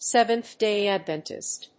アクセントSéventh‐Day Advéntist